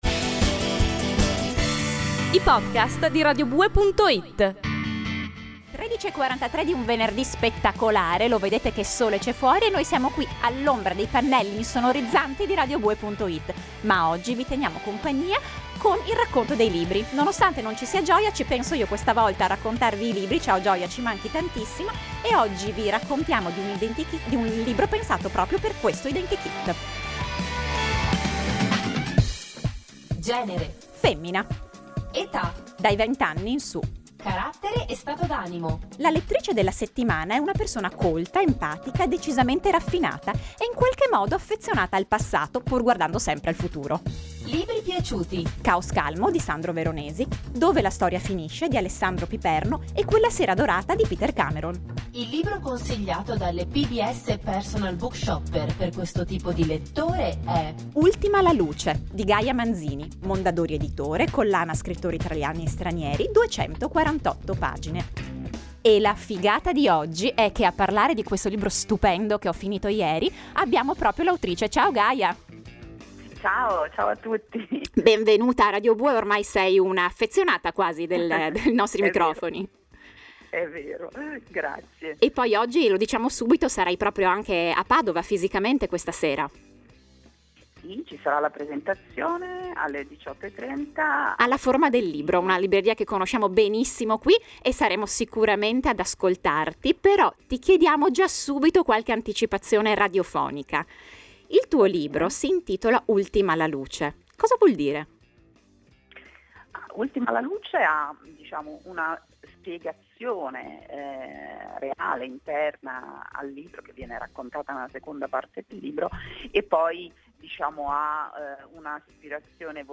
l’abbiamo incontrata in radio
Ascolta la prima parte dell’intervista (o scaricala qui) in cui l’autrice ci svela anche la particolarità della dedica, che sembra uno strano caso di omonimia: